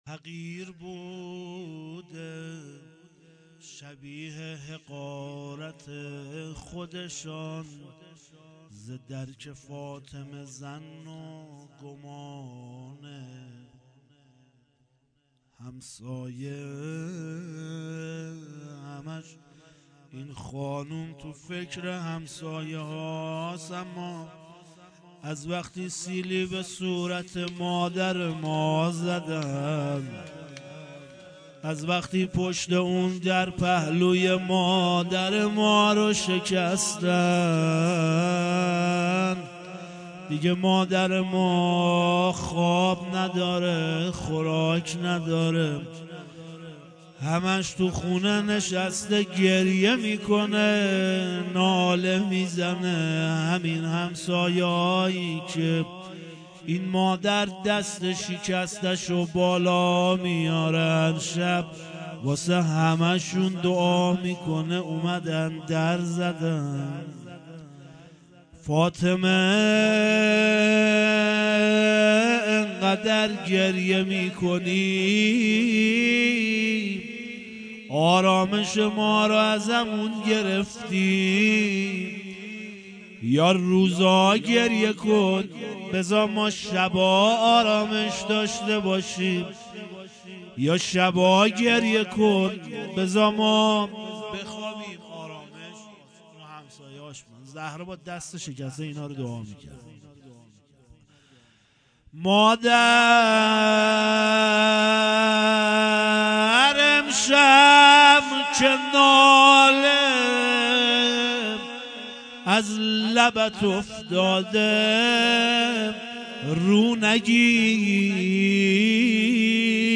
فاطمیه دوم شب اول